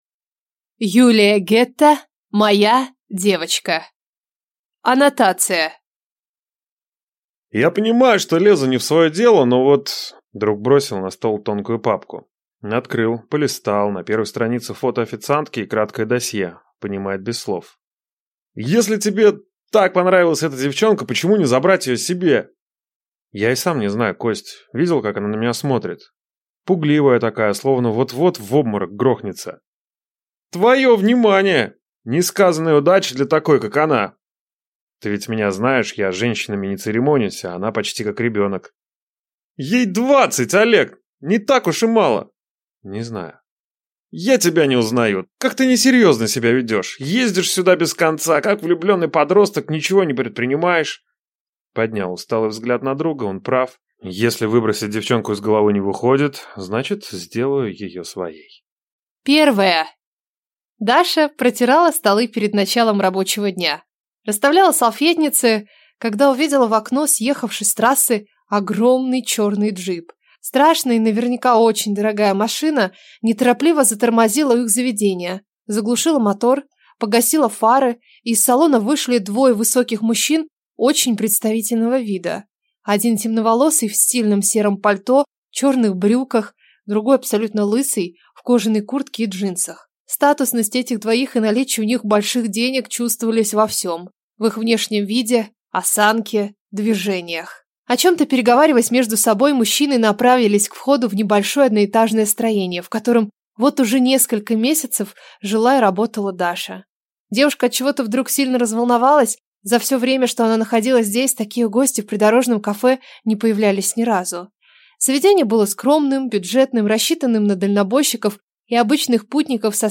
Aудиокнига Моя девочка